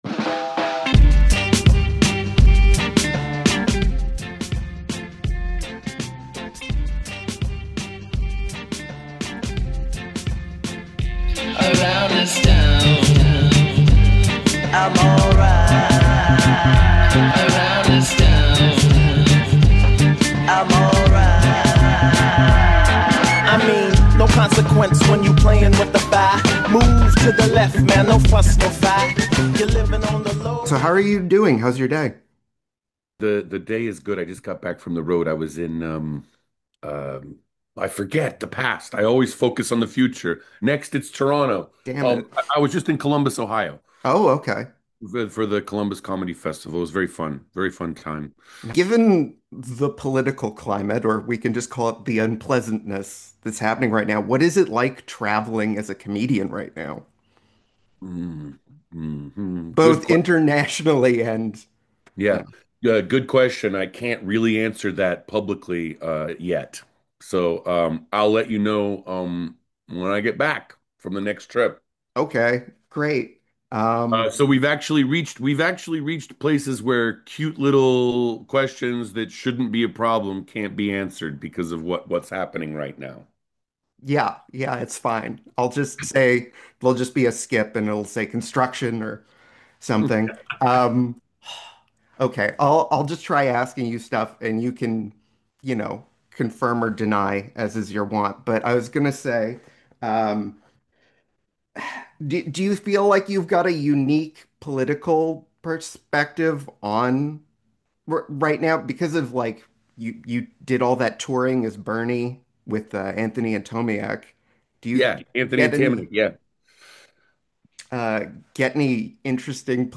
JFL Toronto Interview: Comic and impressionist James Adomian Adomian talks Orson Welles, the bisexuality of Gilgamesh, and why he can't only impersonate bad people
I was thrilled talk with James over Zoom about everything from where an impression comes from to the significance of voices from the under culture, for better or worse... all while my entire house shakes from construction outside my window.